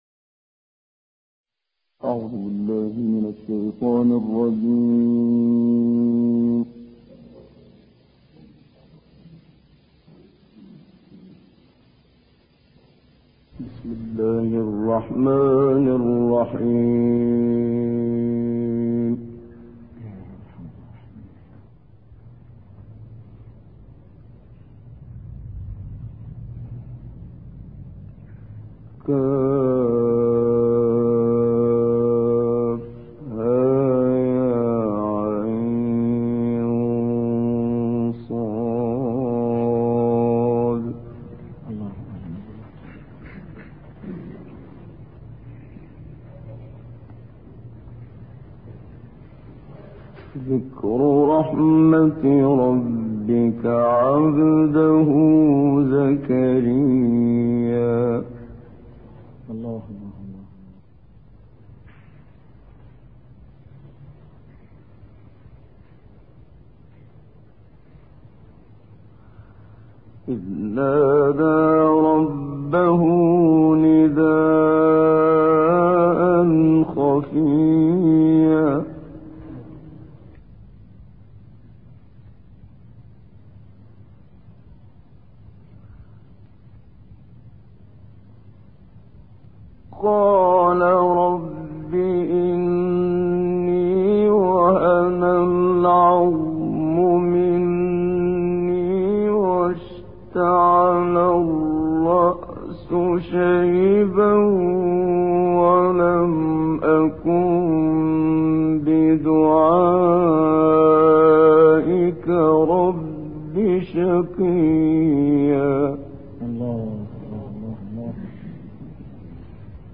در لیبی